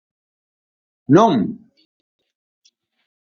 Pronunciado como (IPA)
[nʊ̃ŋ]